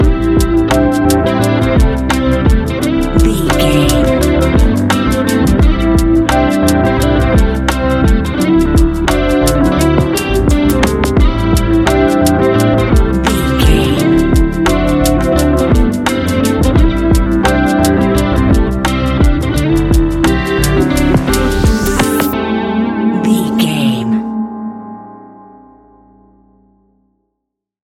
Ionian/Major
chilled
laid back
Lounge
sparse
new age
chilled electronica
ambient
atmospheric